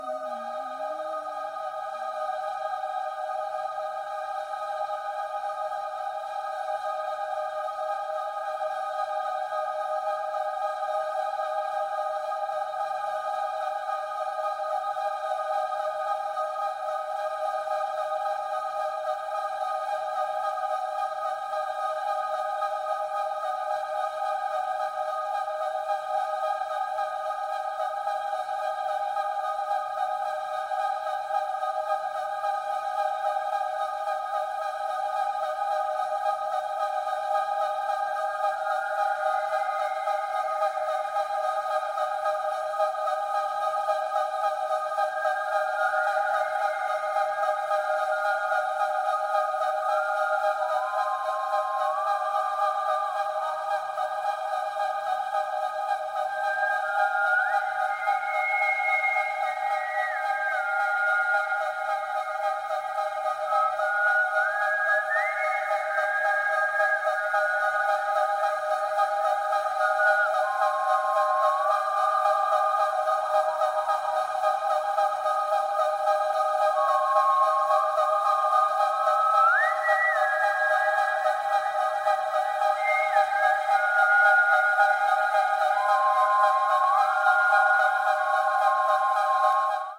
聖歌、イタリアの伝統歌、フルクサス等からインスパイアされた、声によるアンビエント作品！